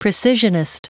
Prononciation du mot precisionist en anglais (fichier audio)